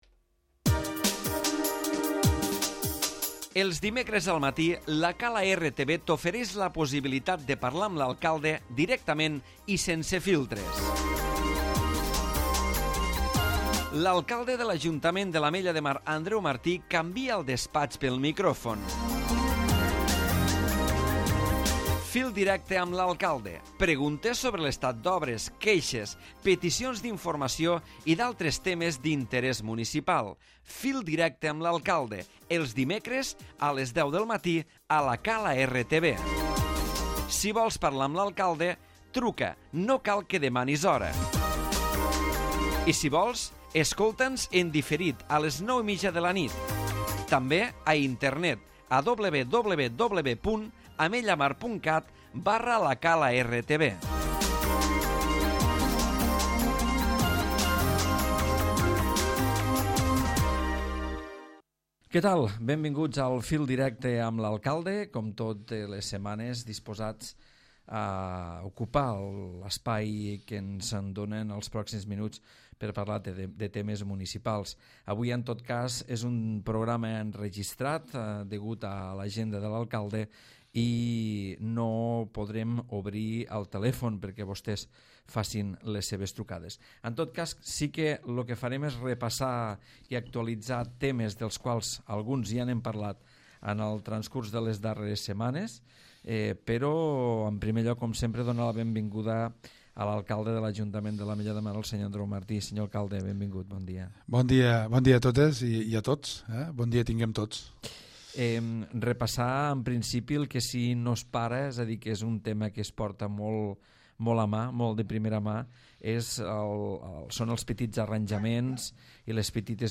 L'alcalde Andreu Martí repassa setmanalment el dia a dia de l'ajuntament i atén a les trucades dels oients.